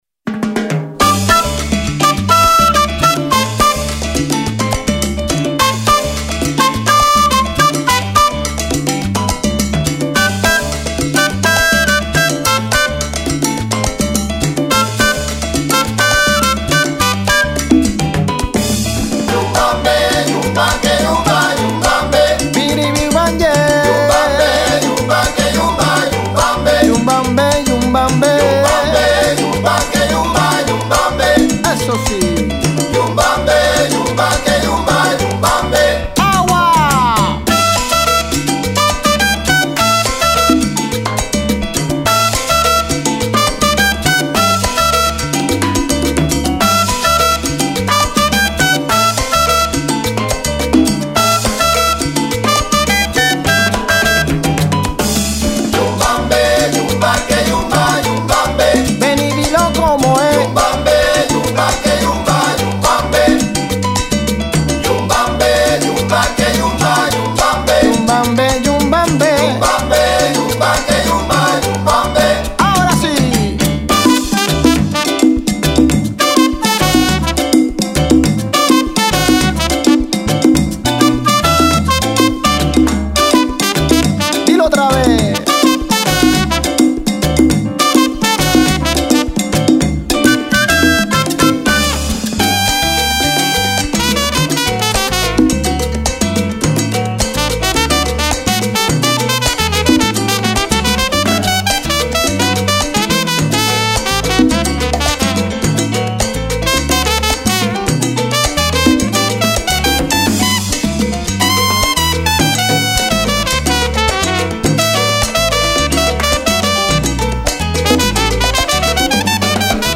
chant, flûte
piano
basse
congas, bongos, chœurs
timbales
trompette, chœurs